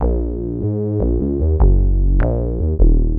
44BASSLOOP-L.wav